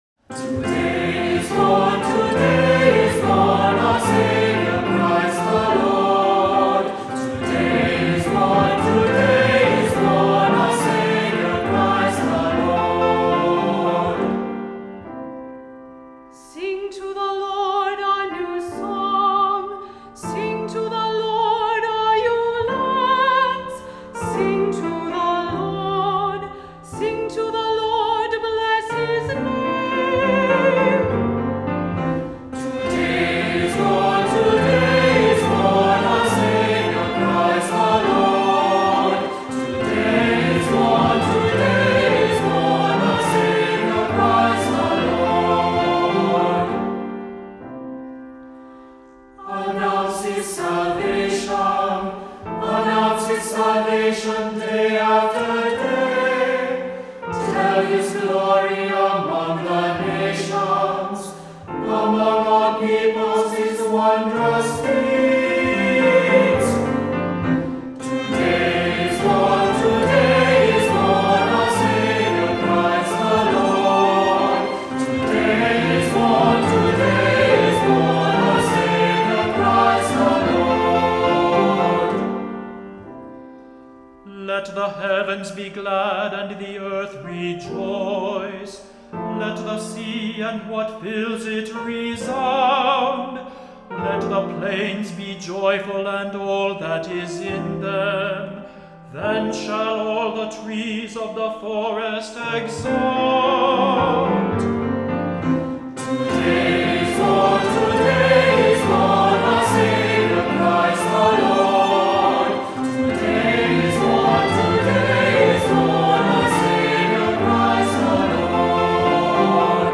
Voicing: Two-part mixed choir; Cantor; Assembly